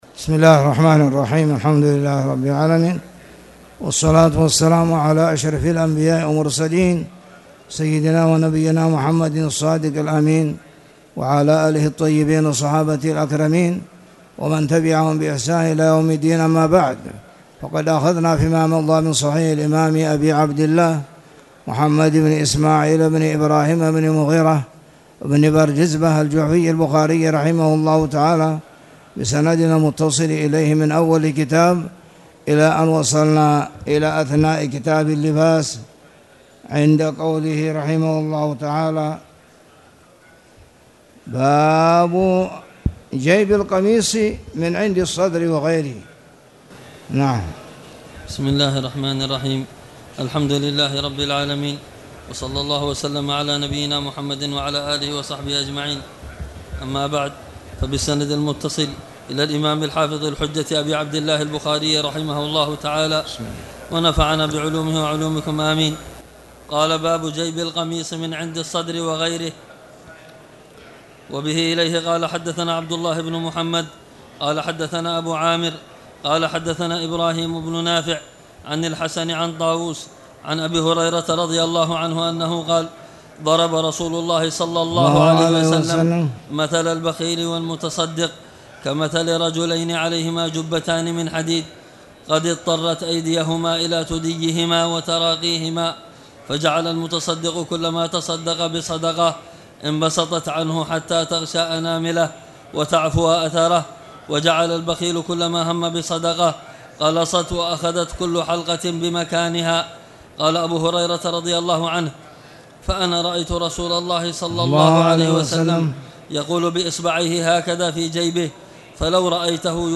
تاريخ النشر ١٧ ربيع الثاني ١٤٣٨ هـ المكان: المسجد الحرام الشيخ